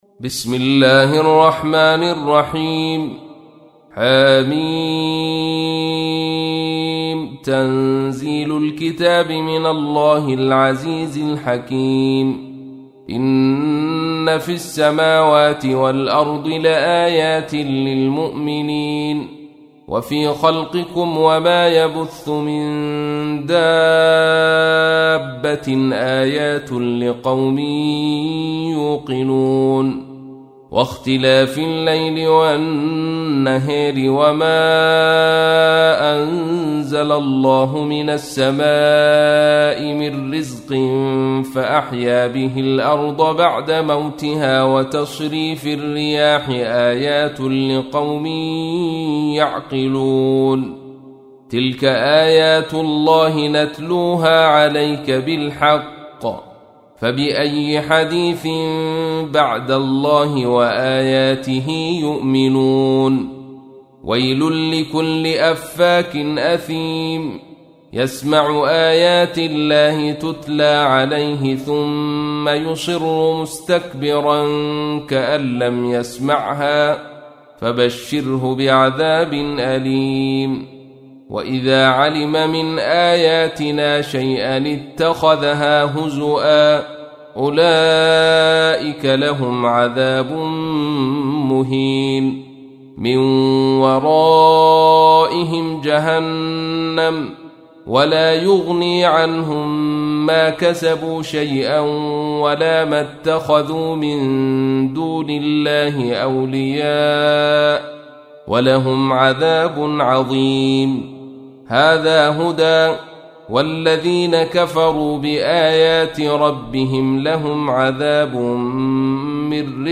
تحميل : 45. سورة الجاثية / القارئ عبد الرشيد صوفي / القرآن الكريم / موقع يا حسين